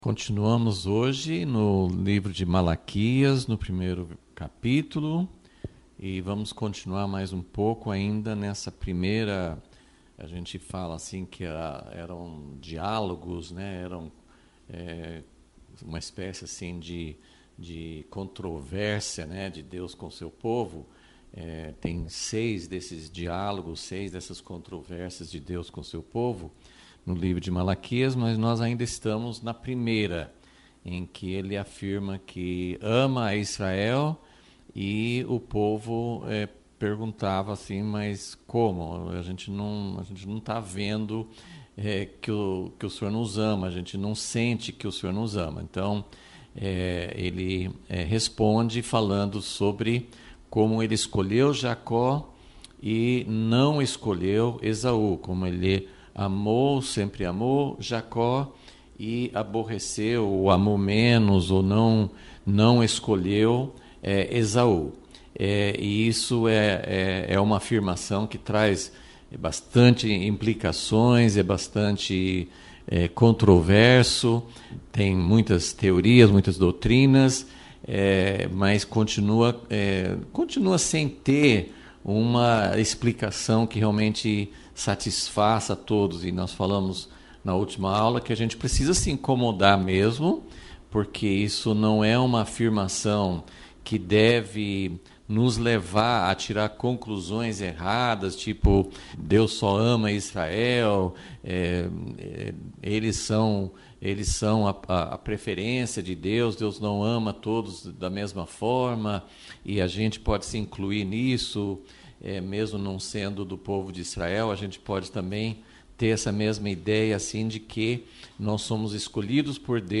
Aula 3 – Vol.37 – Deus ama somente o povo escolhido?